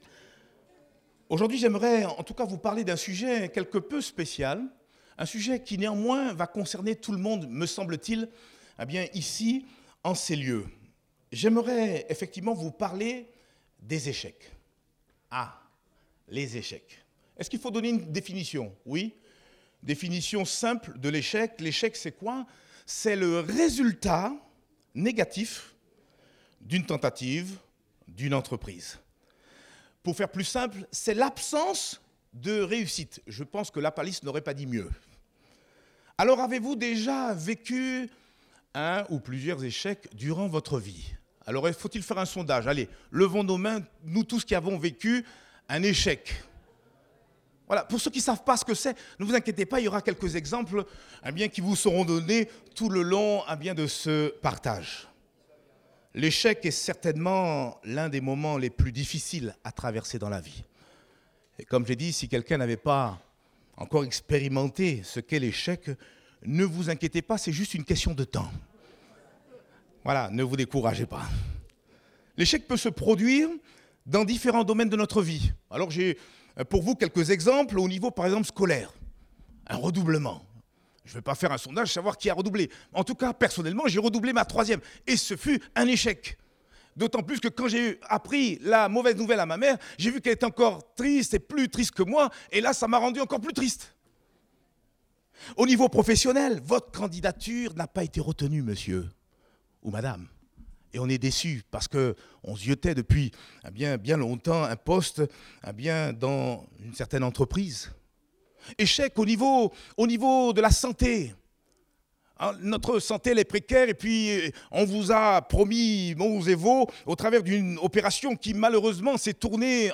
Date : 26 mai 2024 (Culte Dominical)